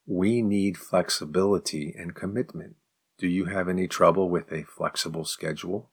10_advanced_question_slow.mp3